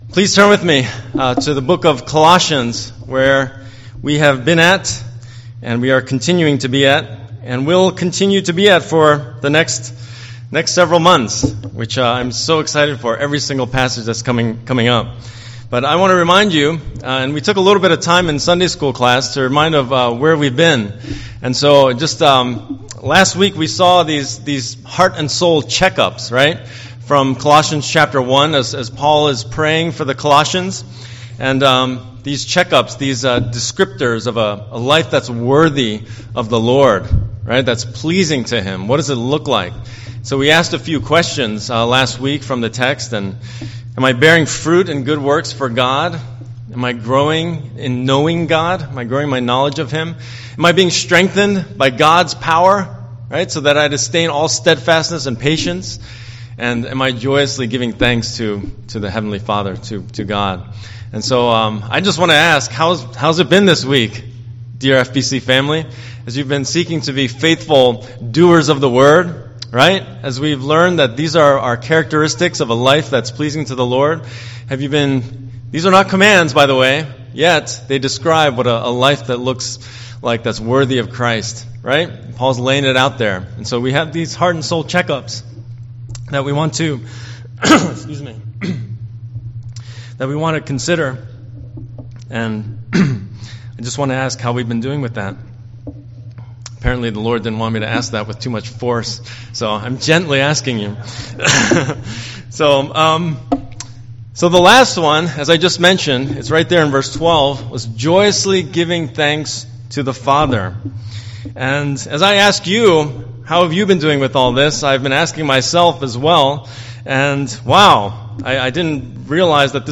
Tag-Archive for ◊ Gospel ◊